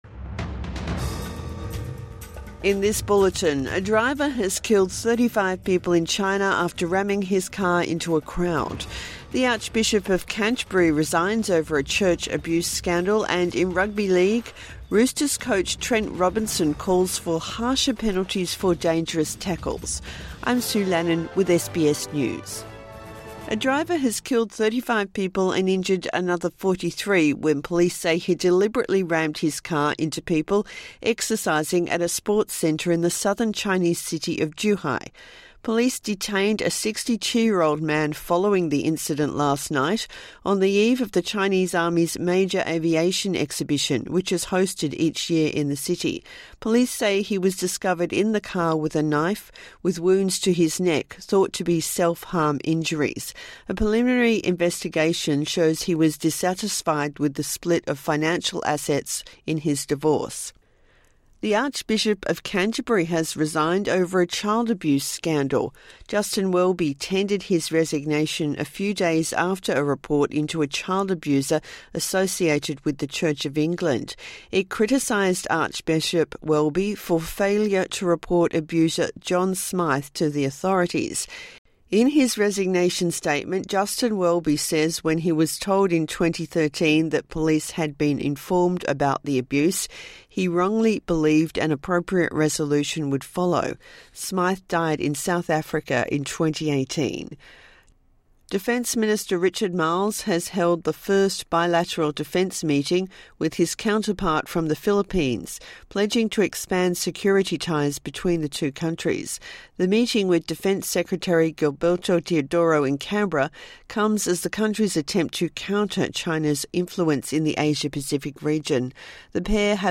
Morning News Bulletin 13 November 2024